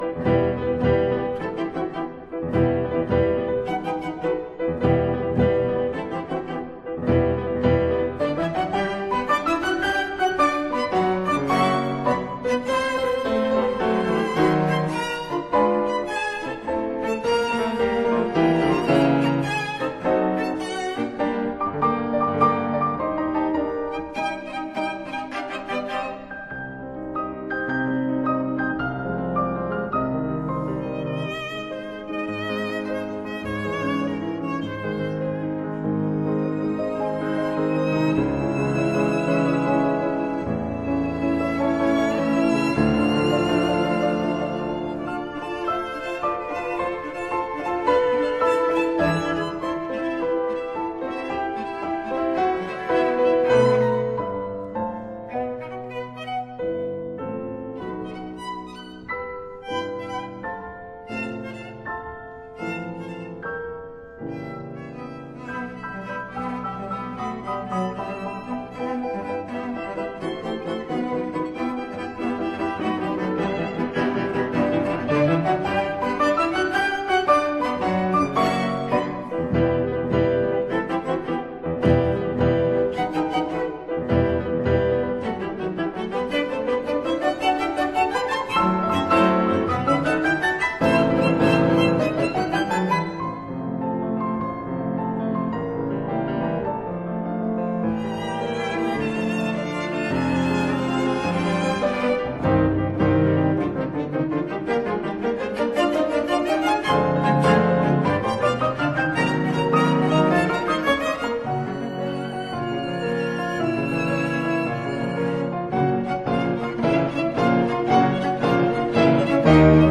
Piano Quartet in e minor
The Presto con fuoco which comes next, as a kind of scherzo, again has very contrasting and powerful middle section to it, as a kind of trio.